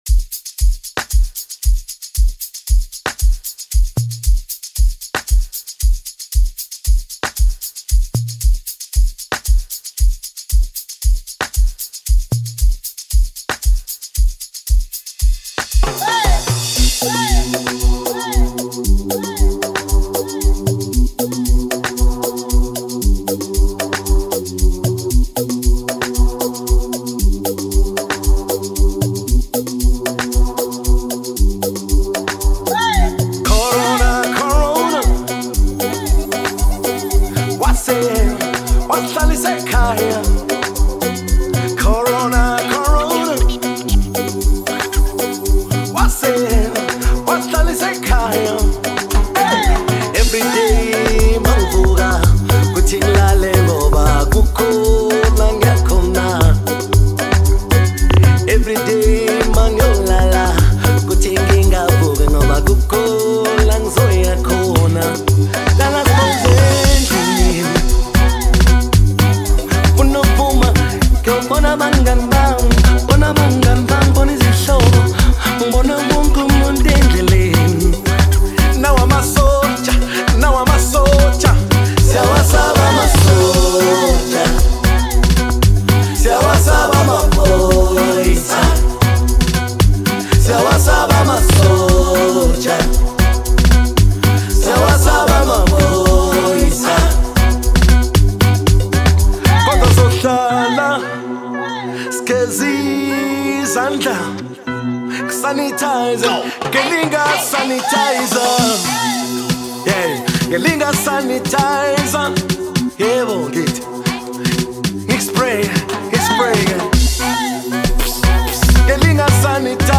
Amapiano single
keys